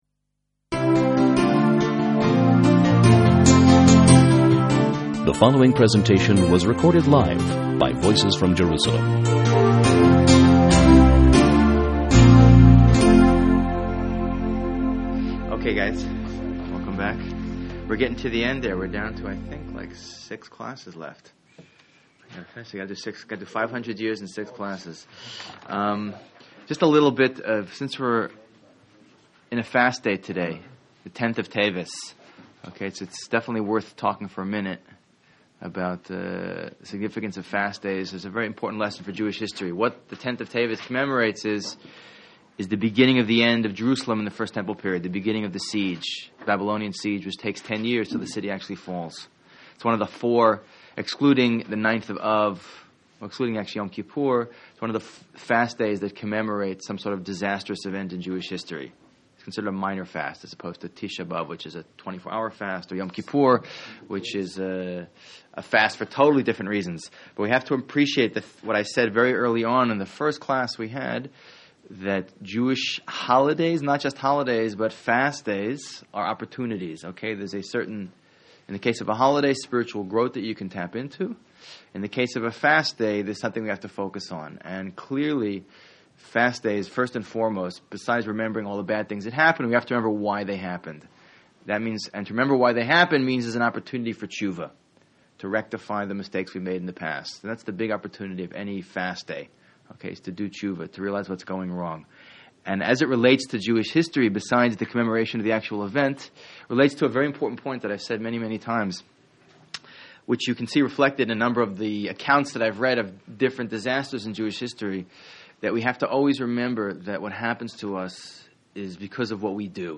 Outline or Thoughts on this Lecture Commenting is not available in this channel entry.